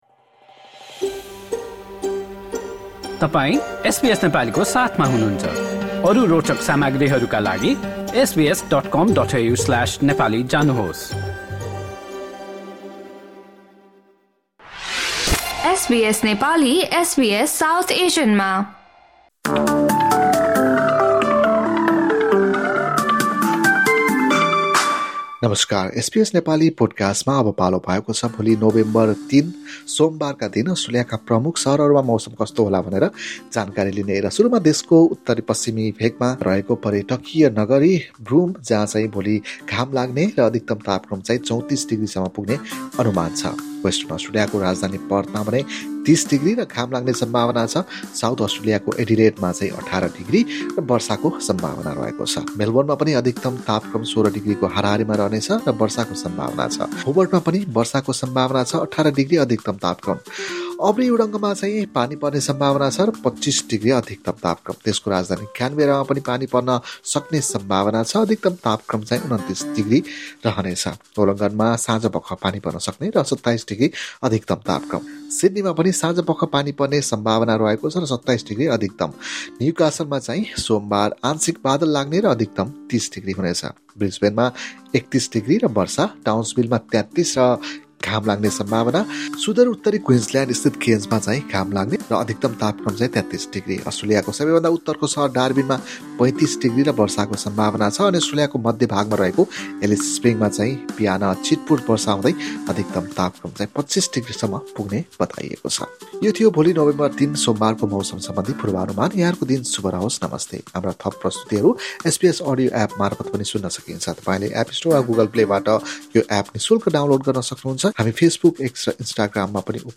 सोमवार, ३ नोभेम्बरको अस्ट्रेलियन मौसम अपडेट नेपाली भाषामा सुन्नुहोस्